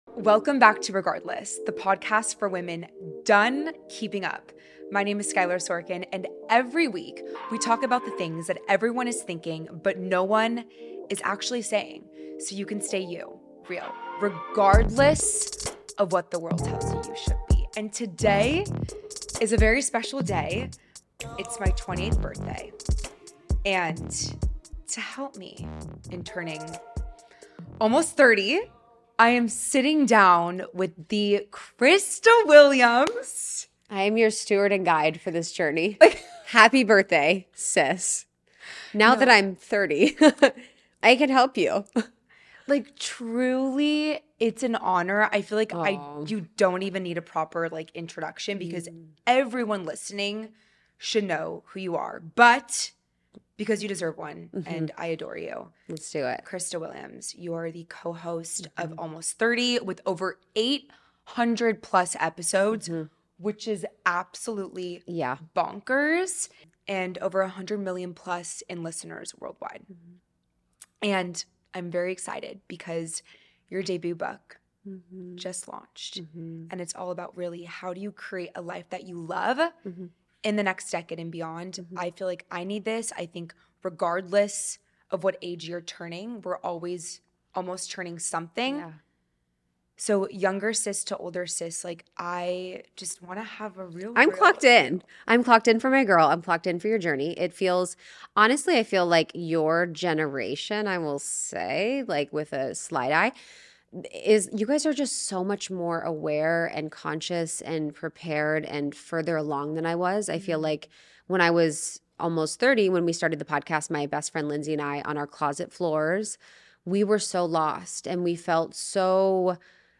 with real, multigenerational conversations alongside her licensed therapist mom